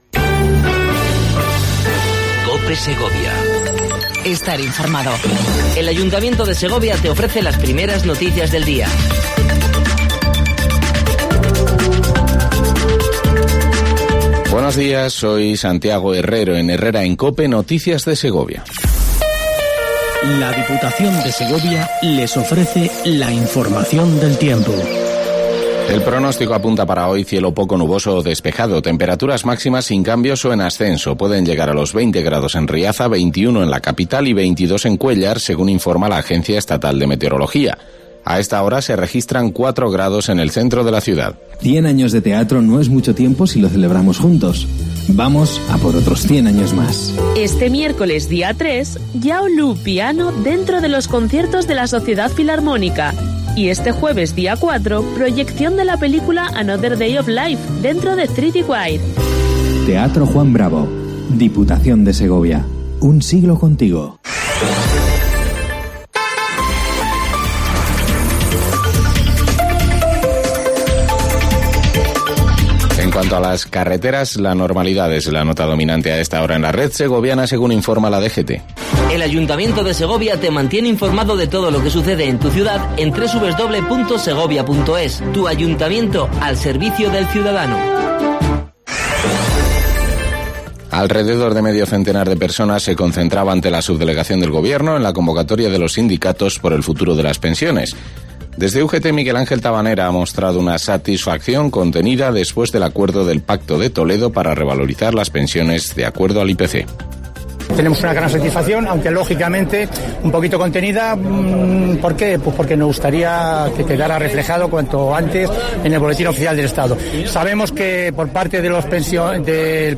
AUDIO: PRIMER INFORMATIVO MATINAL COPE SEGOVIA